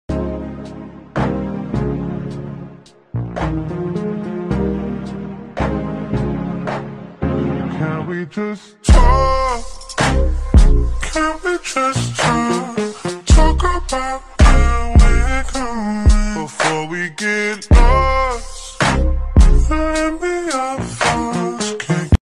slowed n pitch